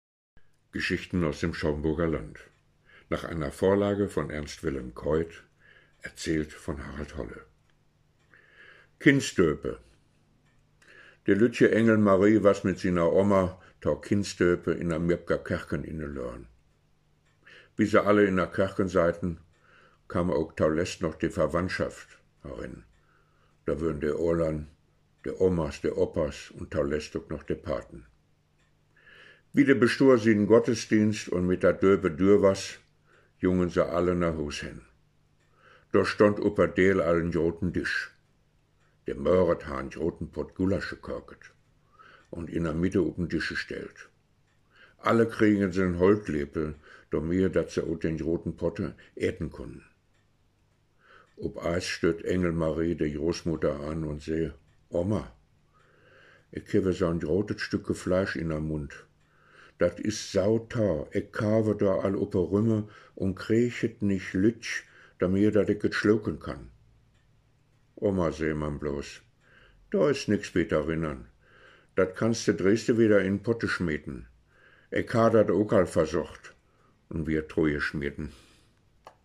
Schaumburger Platt